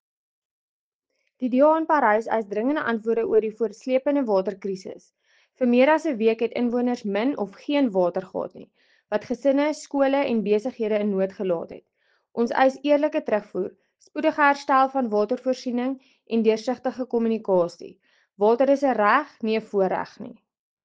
Afrikaans soundbites by Cllr Marié la Cock.